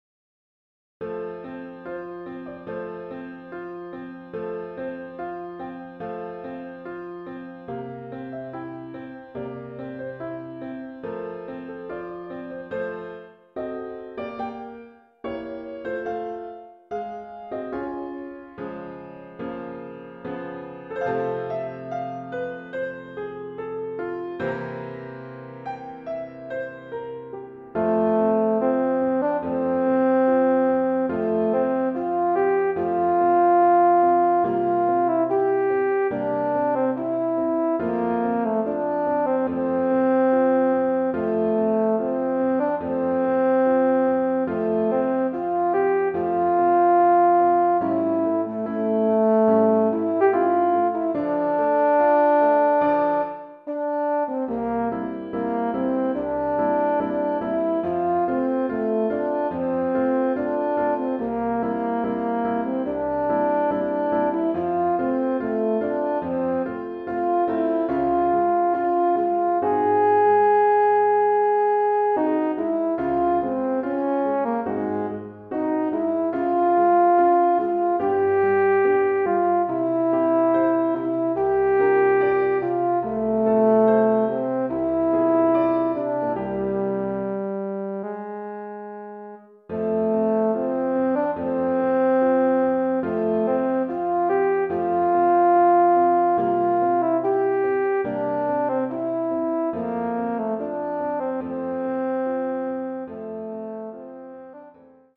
Voicing: Euphonium Solo